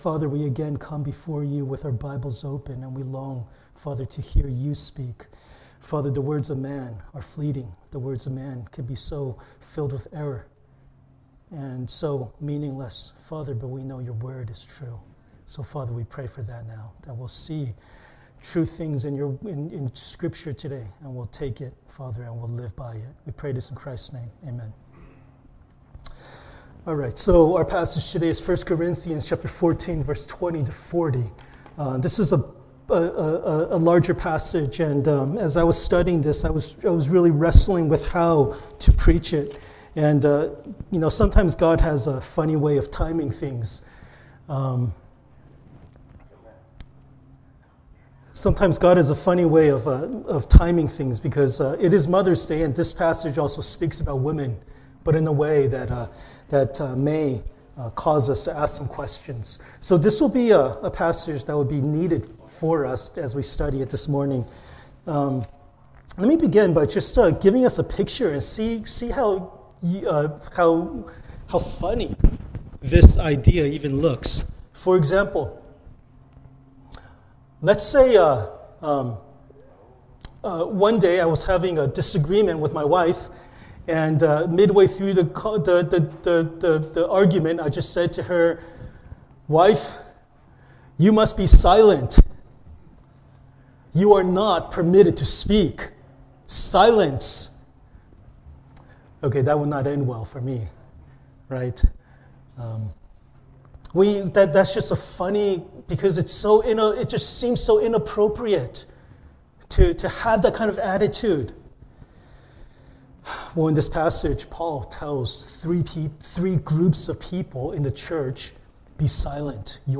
1 Corinthians Passage: 1 Corinthians 14:20-40 Service Type: Sunday Worship 14:20 Brothers